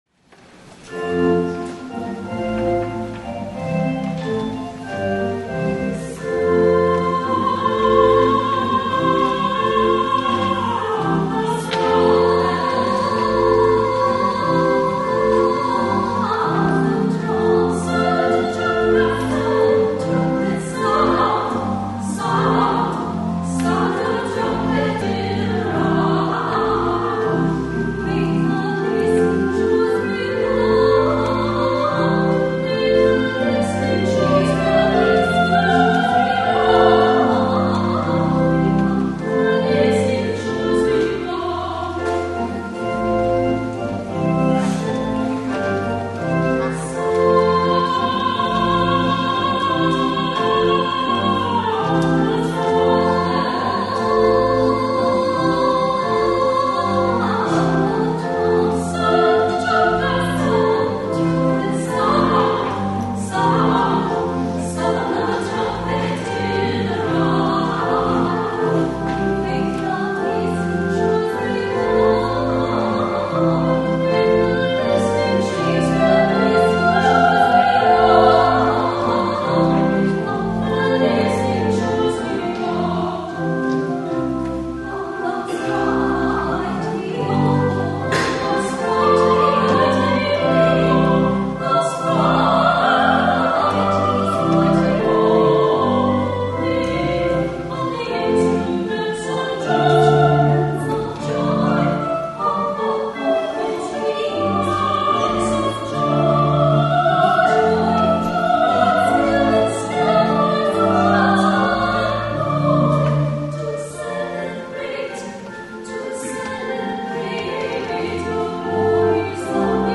soloists